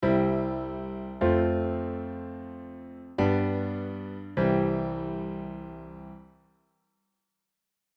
やっぱりサブドミナントさん間にいるとなんだか丸くおさまっているよねっ。
トニックさんとドミナントさんもすっごく落ち着いて聴こえるよっ！